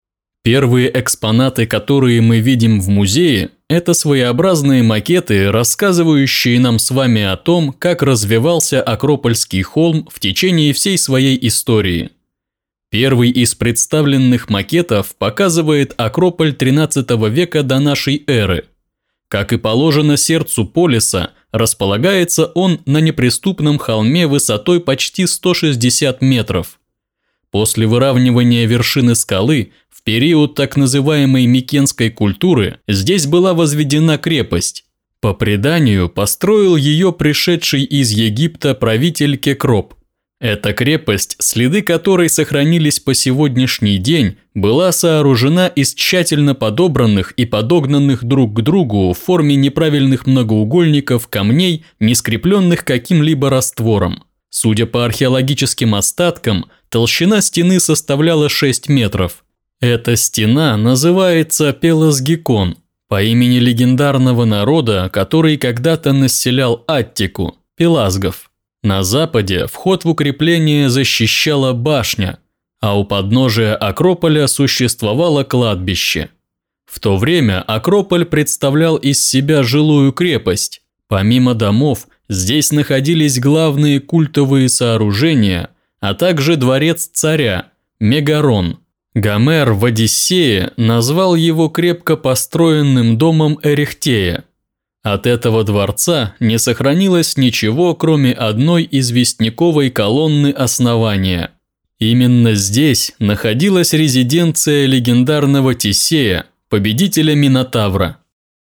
Муж, Закадровый текст/Средний
Focusrite 2i2 2nd gen., Audio-Technica AT2035